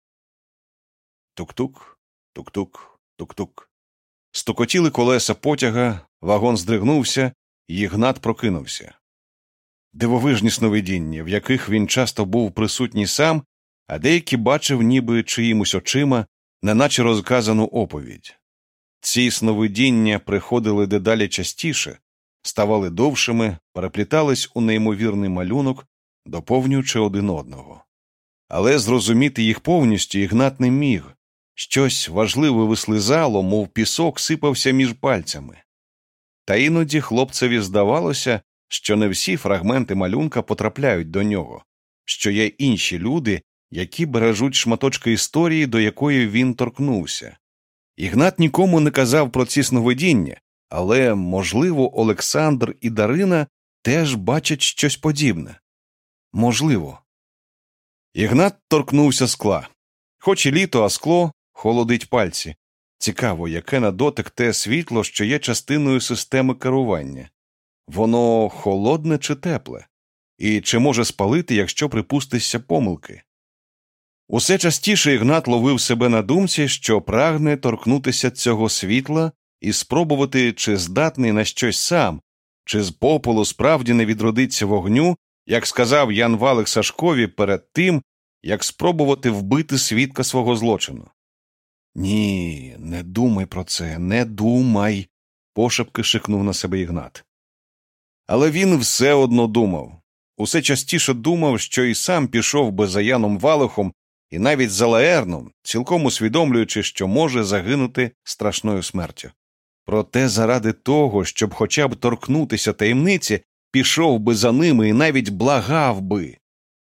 Світи під ногами: аудіокнига українською – Наталія Дев’ятко